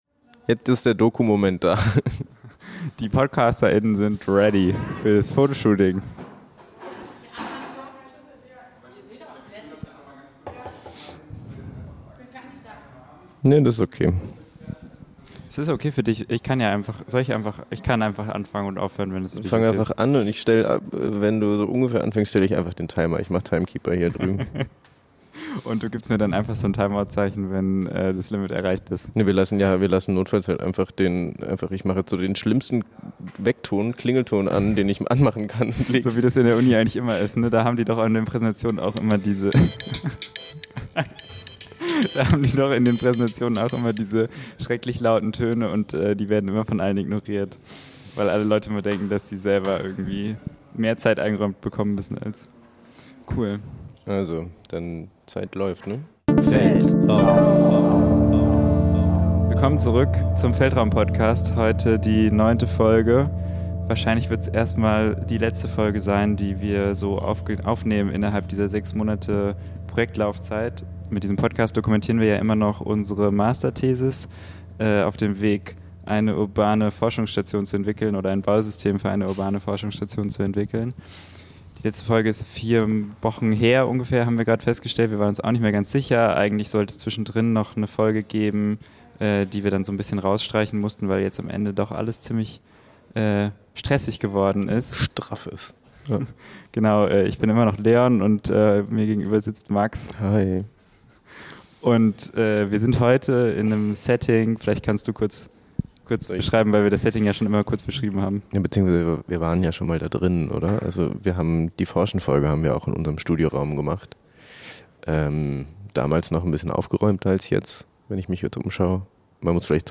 Im Hintergrund die Master-Klasse, die auch alle fertig werden möchten.